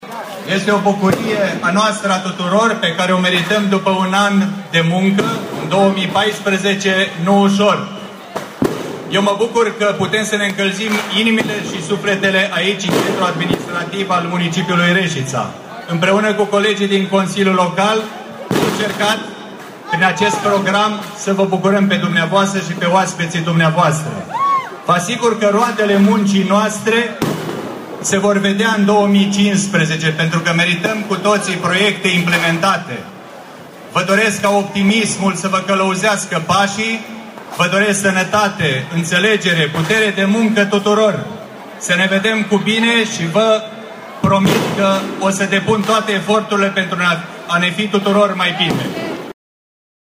La miezul nopţii, primarul Mihai Stepanescu a transmis un mesaj tuturor reşiţenilor: